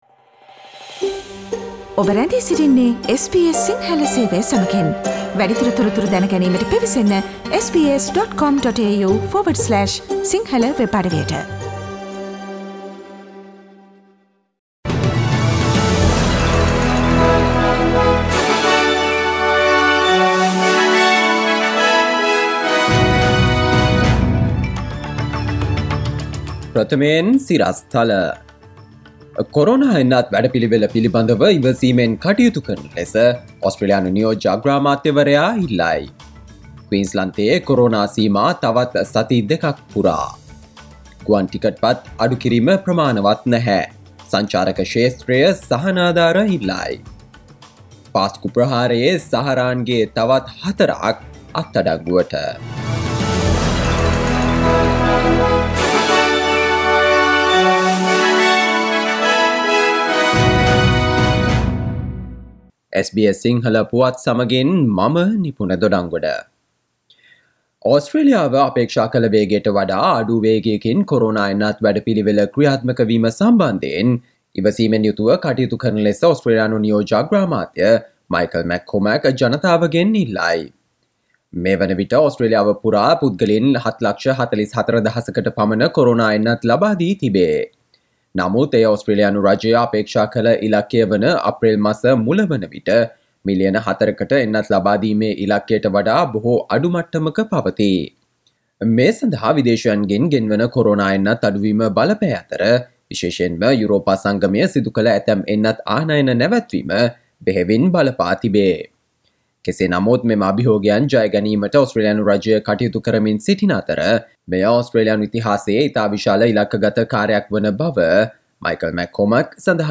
Here are the most prominent Australian, Sri Lankan, International, and Sports news highlights from SBS Sinhala radio daily news bulletin on Friday 2 April 2021.